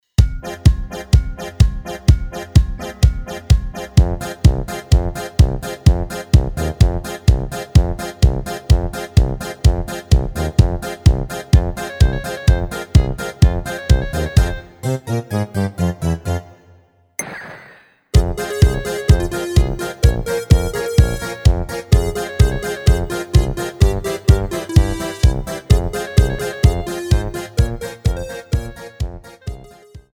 Rubrika: Pop, rock, beat
Karaoke
HUDEBNÍ PODKLADY V AUDIO A VIDEO SOUBORECH